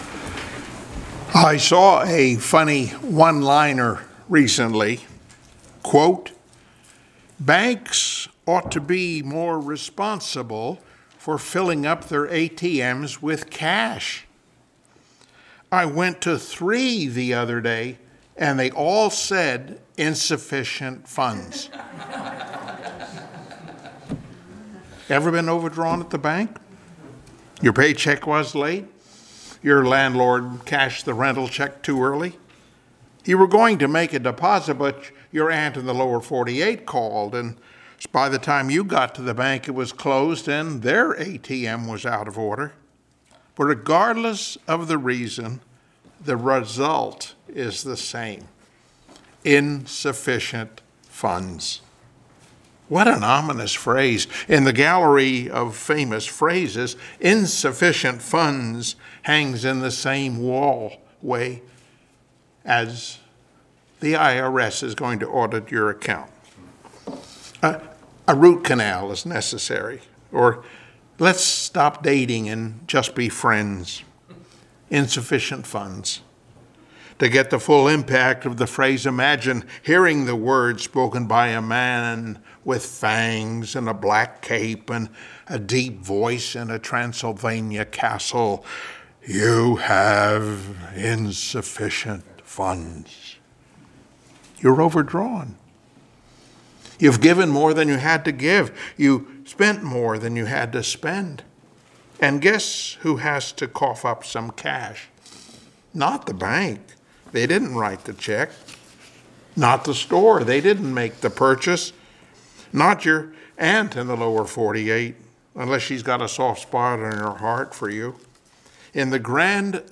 Service Type: Sunday Morning Worship Topics: A Debt We Cannot Pay , God Paid What He Did Not Owe , Overcoming Guilt and Shame , Roof of Protection